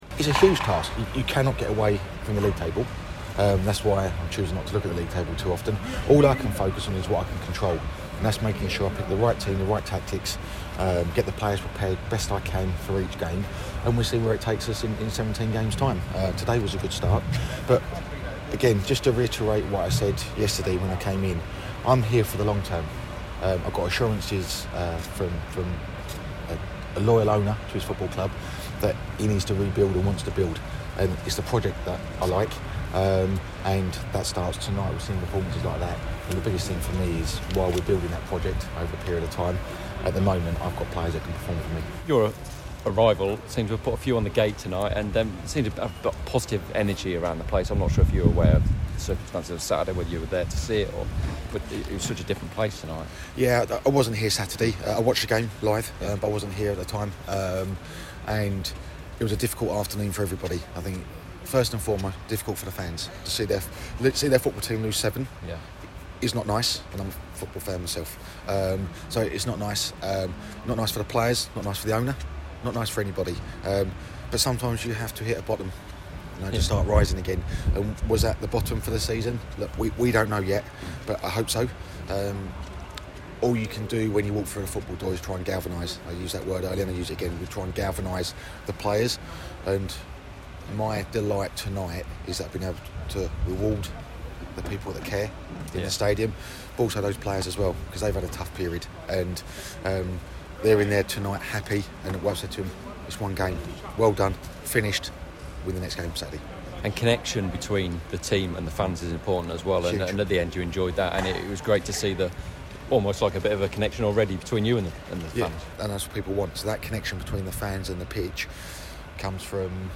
They beat Crewe Alexandra 1-0 at Priestfield last night - thanks to a first half penalty scored by Danny Lloyd. The boss, who only took over at the club on Monday, spoke to us after the match